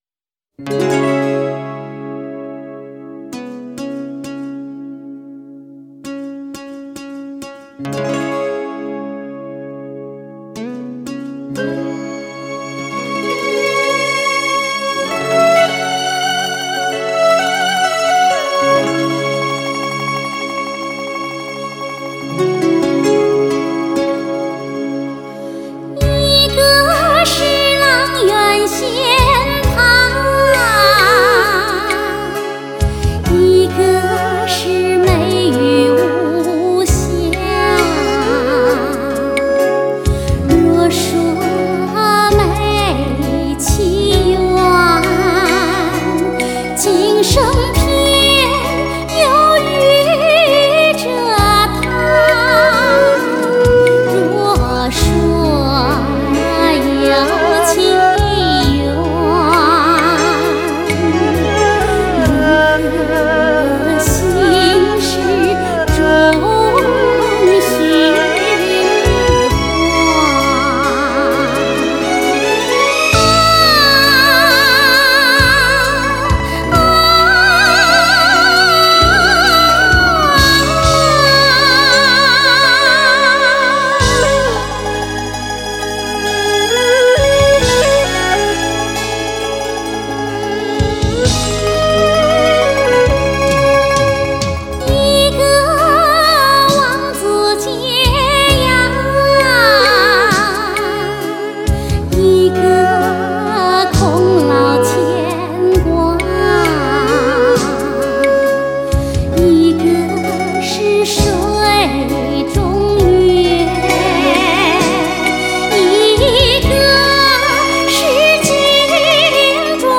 极致的双频模数还原，让你感受非同凡响的民歌韵魅！
音声超级宽阔，人声真实，愉悦的类比音质感受！